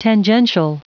Prononciation du mot : tangential
tangential.wav